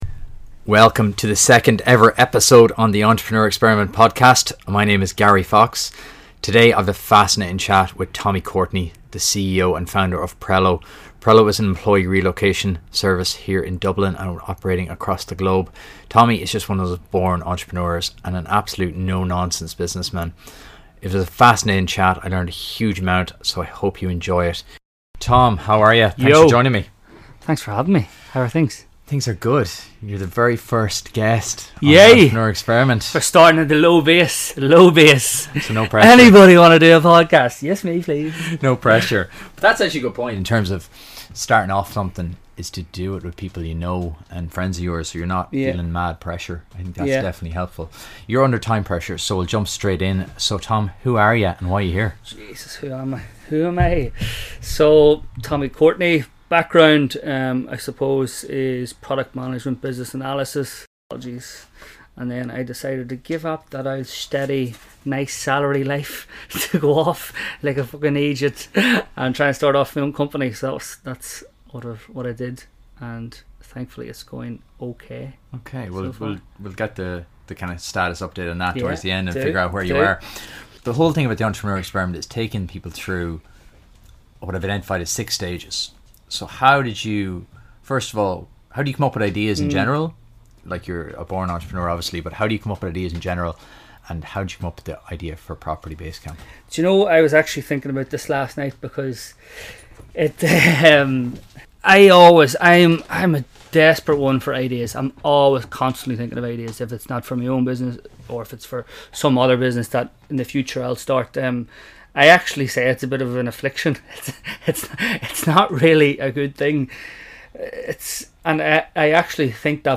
Episode 2 is an in-depth interview